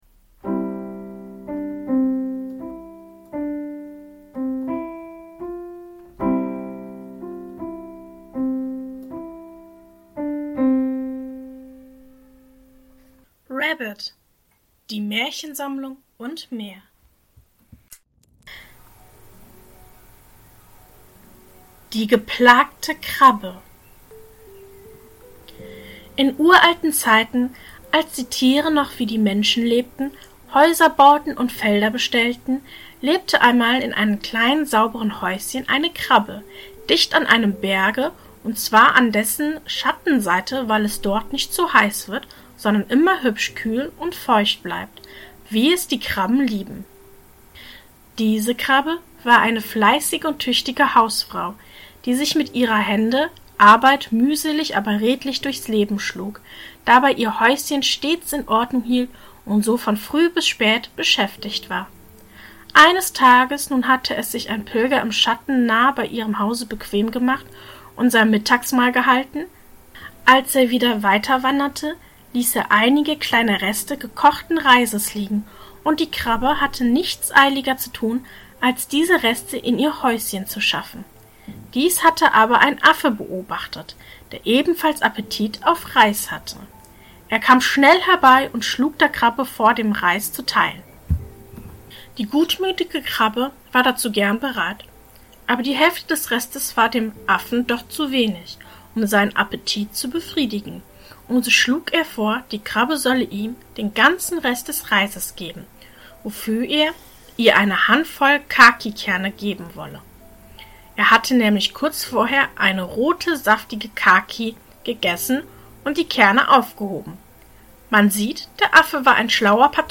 In der heutigen Folge lese ich Folgendes vor: 1. Die geplagte Krabbe. 2.